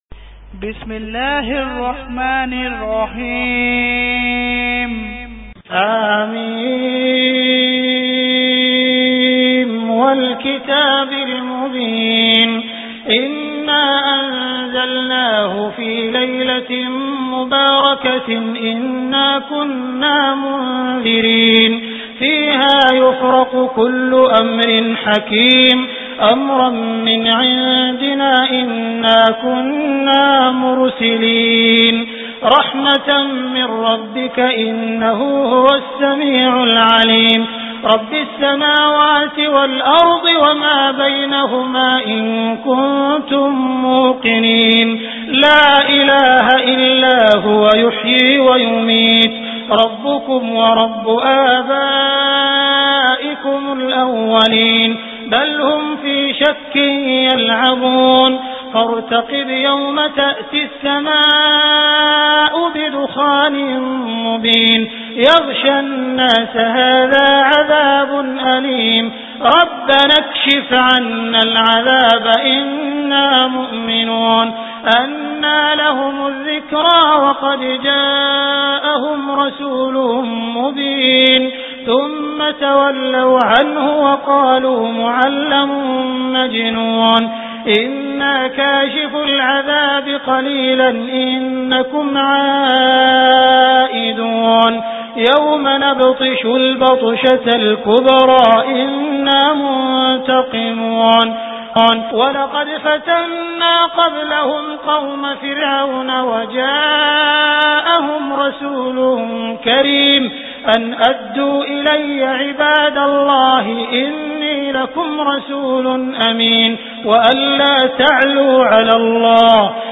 Surah Ad Dukhan Beautiful Recitation MP3 Download By Abdul Rahman Al Sudais in best audio quality.